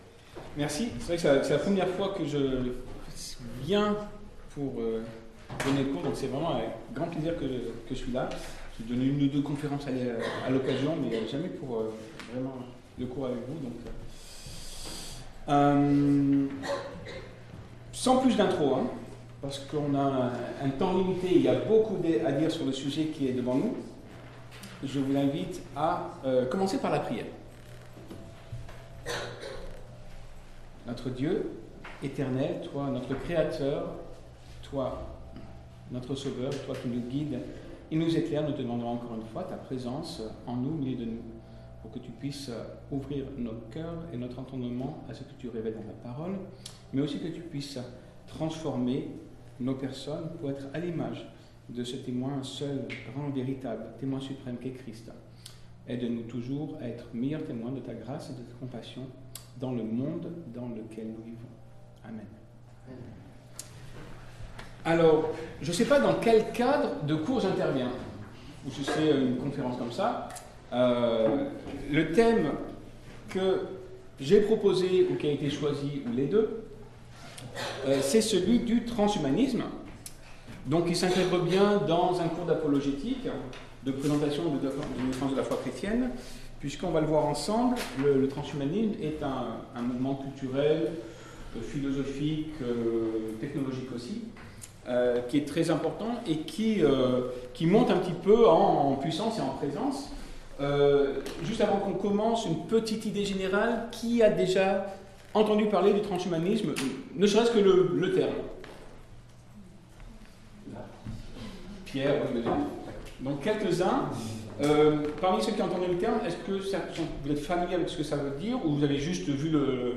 Mini-séminaire sur le transhumanisme